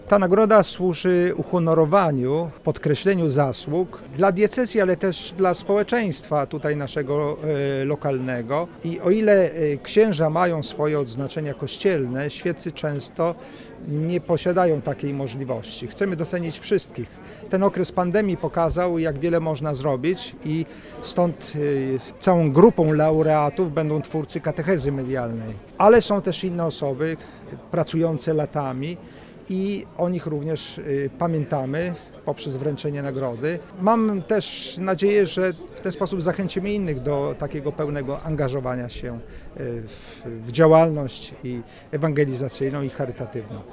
Dziś podczas uroczystej gali w Sandomierzu wręczono nagrody biskupa sandomierskiego Krzysztofa Nitkiewicza „Arbor Bona”, czyli Dobrego Drzewa.
Mówi biskup Krzysztof Nitkiewicz: